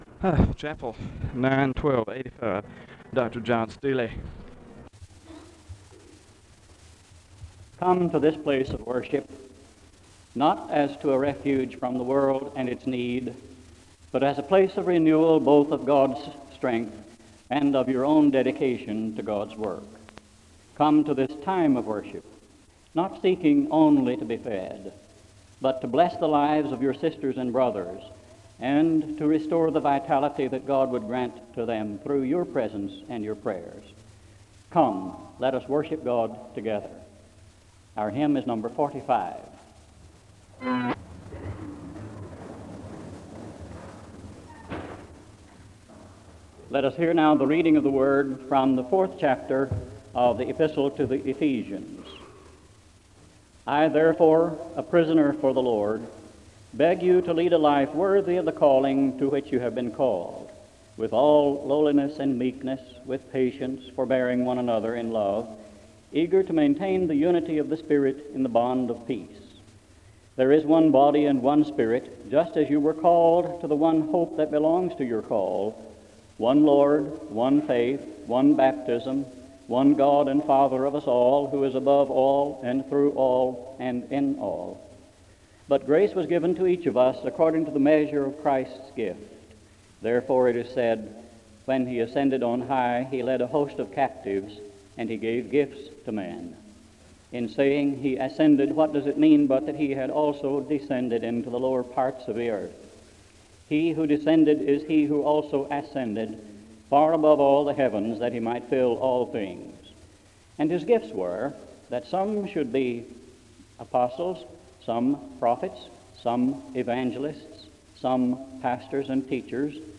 The service begins with a welcome (0:00-0:47). There is a Scripture reading from Ephesians 4 and a word of prayer (0:48-5:26).
SEBTS Chapel and Special Event Recordings - 1980s